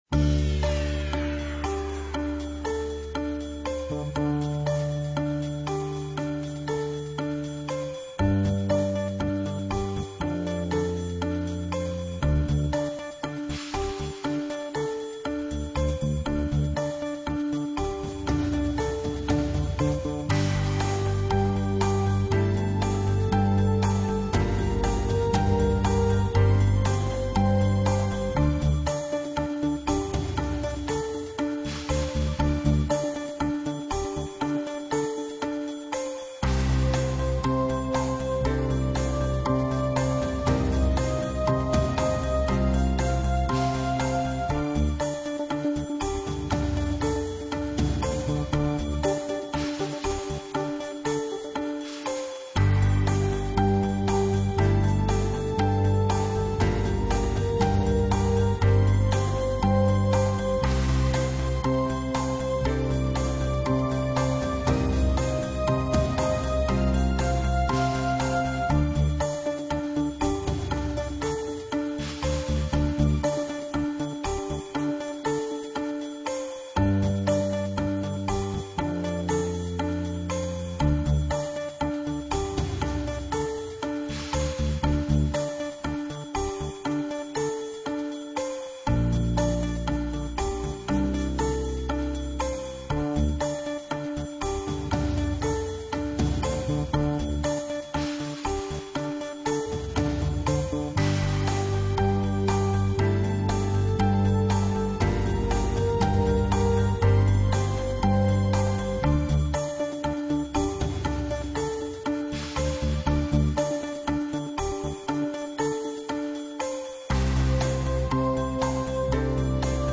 На этой странице представлены звуки колеса фортуны в разных вариациях: от классического вращения до эффектных фанфар при выигрыше.
Фоновый звук для вращения колеса удачи